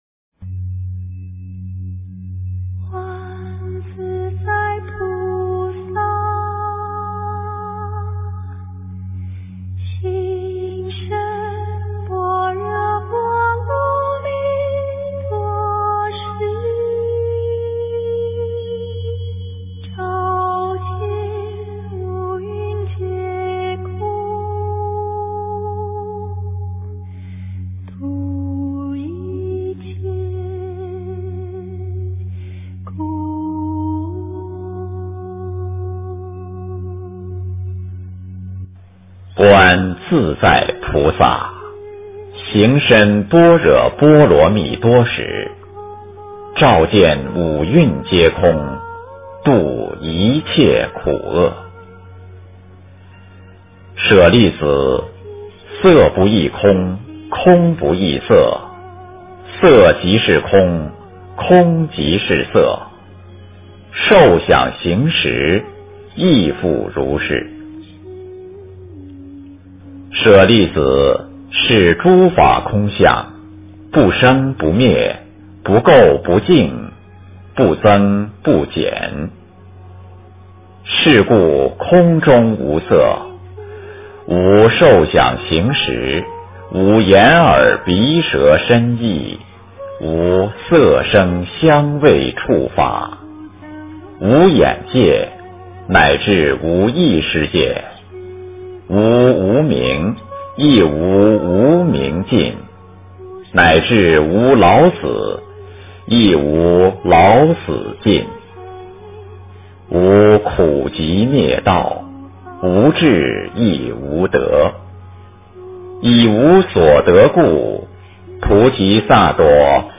心经-念诵
标签: 佛音 诵经 佛教音乐